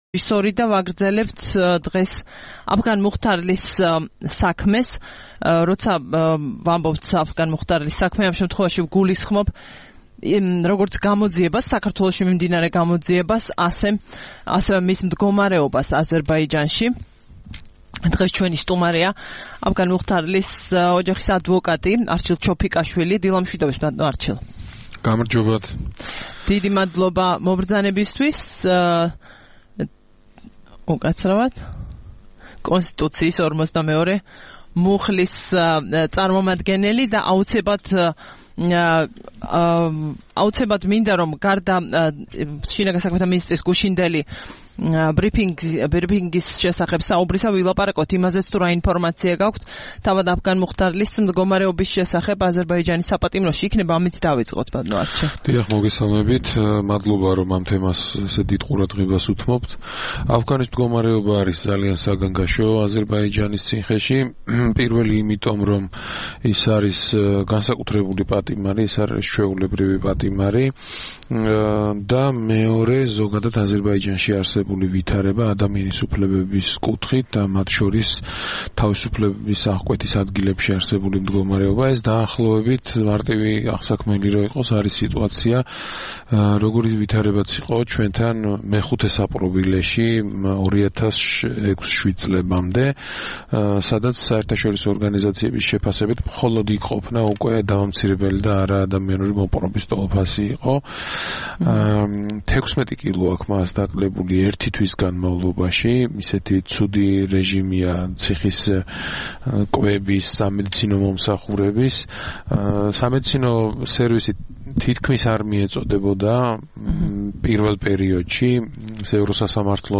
სტუმრად ჩვენს ეთერში: